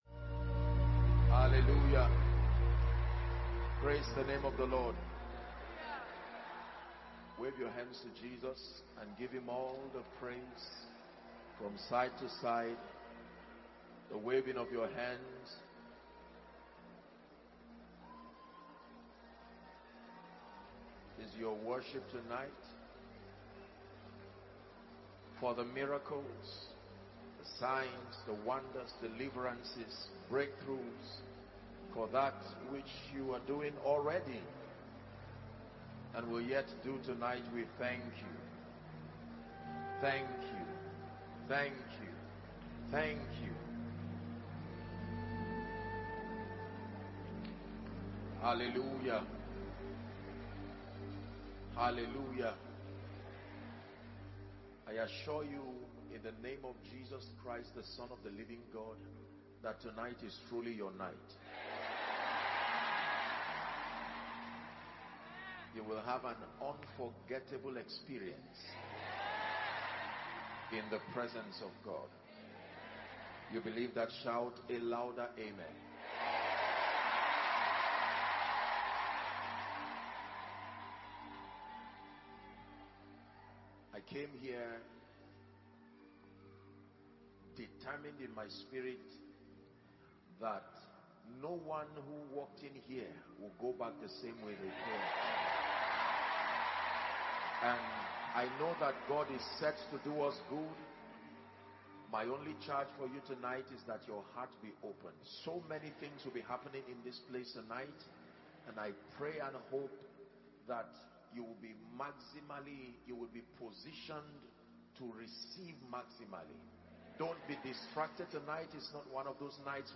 Title: Preserving Territorial Revival – Miracle & Impartation Service (The Sound of Revival Conference – Koinonia UK)